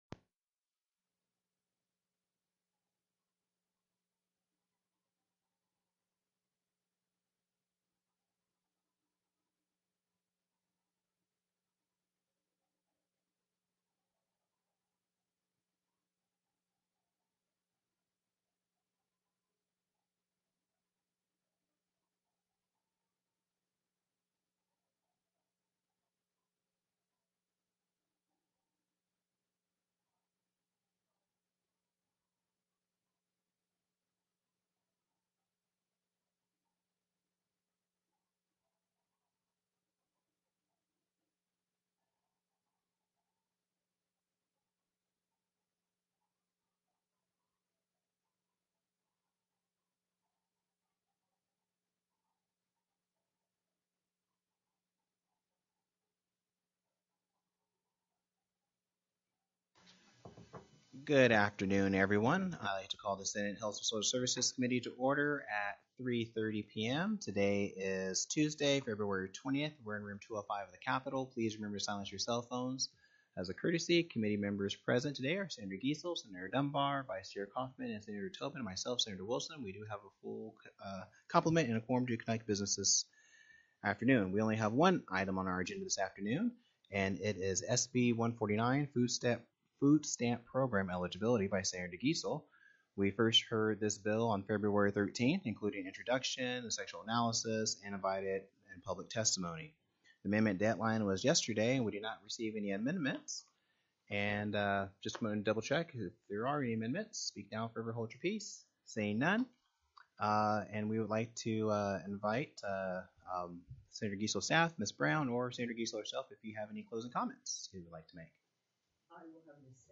02/20/2024 03:30 PM Senate HEALTH & SOCIAL SERVICES
The audio recordings are captured by our records offices as the official record of the meeting and will have more accurate timestamps.
+ teleconferenced
CHAIR WILSON announced the consideration of SENATE BILL NO. 149